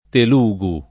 [ tel 2g u ]